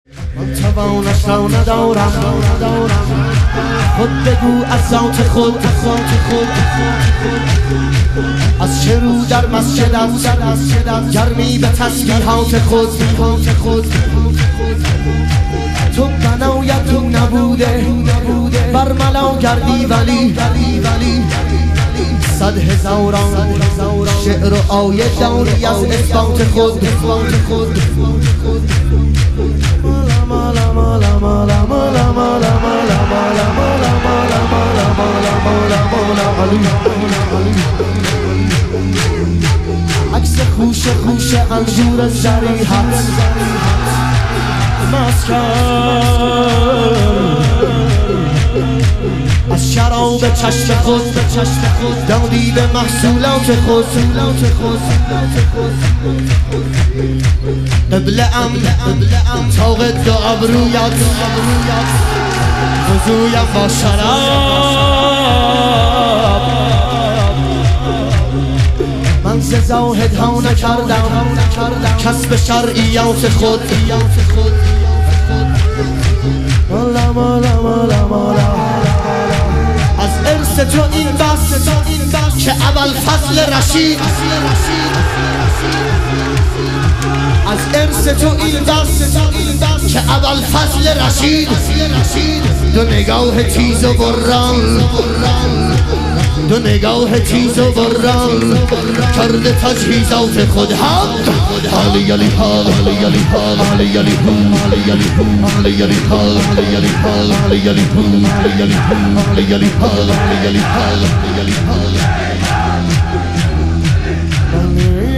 شور
شب شهادت امام صادق علیه السلام